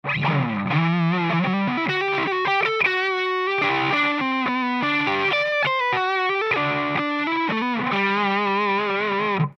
humbucker_verzerrt.mp3